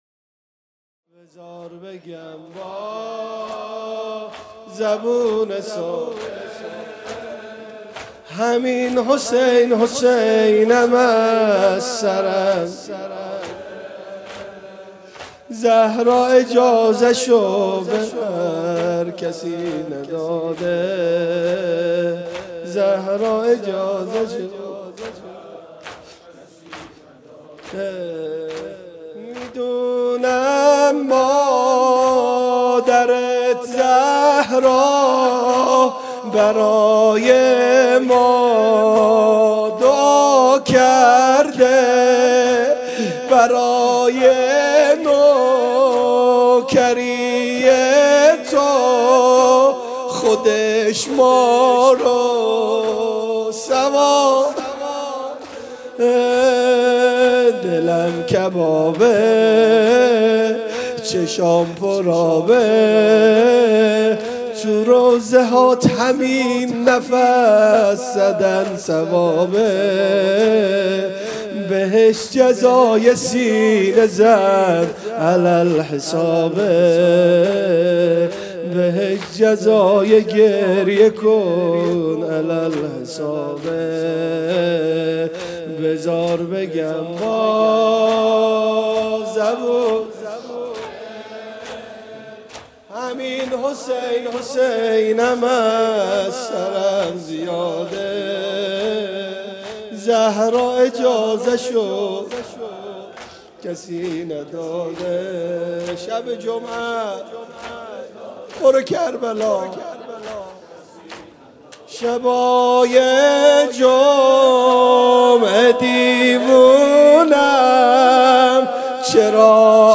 شام غریبان محرم 97 - هیئت شبان القاسم - بذار بگم با زبون ساده